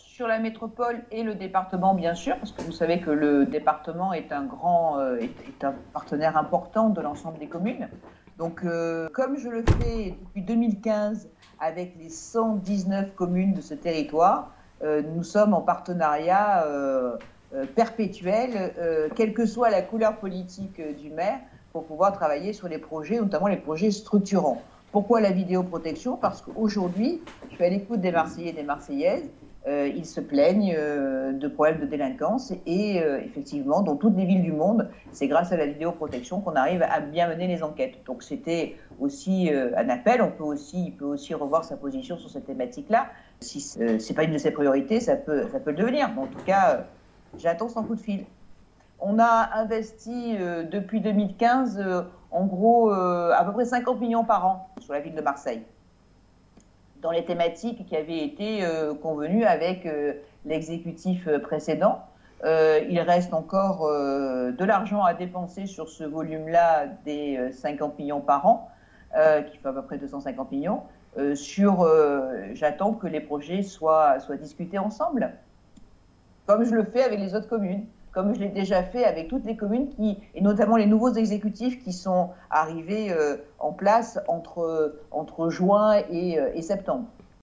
Vœux à la presse de Martine Vassal : -Nous avons réalisé des promesses des années 70-
Questions-réponses